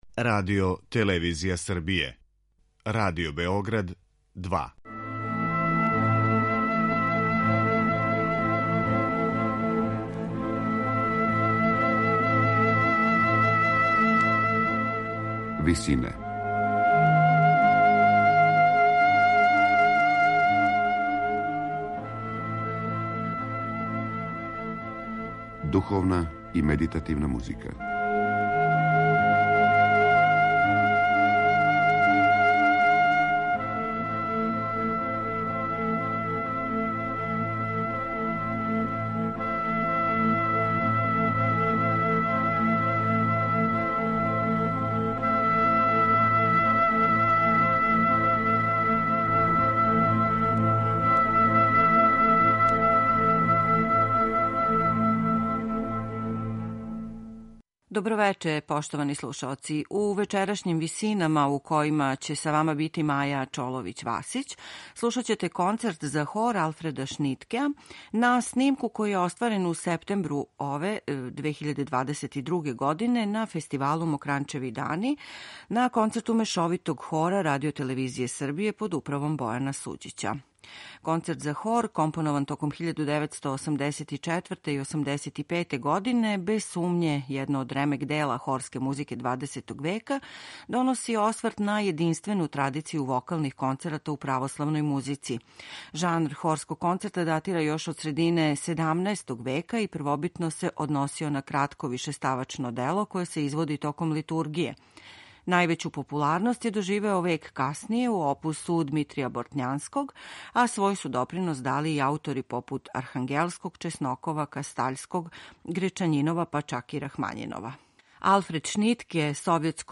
Проширивши идеју и форму Шнитке је у својој вишеставачној композицији евоцирао старе стилове певања, али без директног цитирања напева.
У вечерашњим Висинама емитоваћемо снимак Хора РТС остварен на концерту одржаном 15. септембра 2022. године у цркви Свете Тројице у Неготину на фестивалу Мокрањчеви дани .